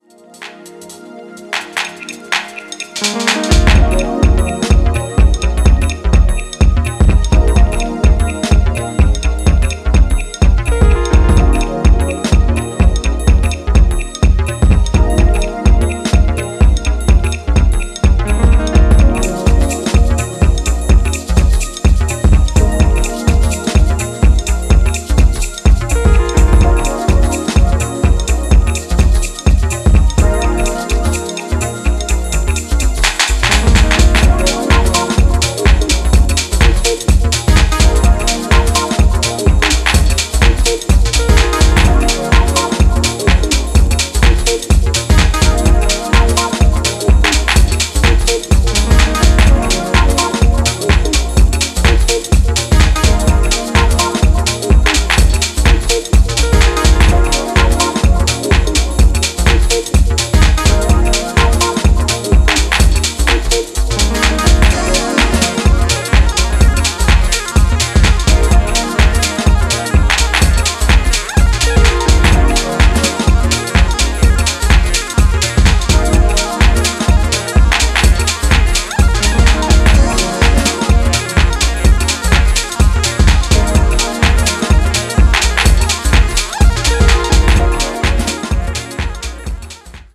ジャンル(スタイル) DEEP HOUSE / TECHNO